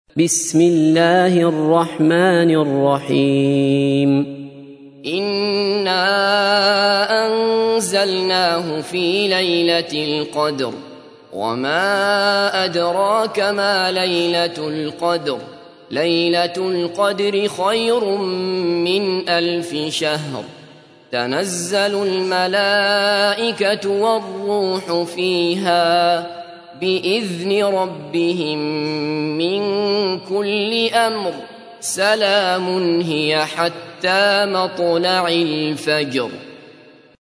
تحميل : 97. سورة القدر / القارئ عبد الله بصفر / القرآن الكريم / موقع يا حسين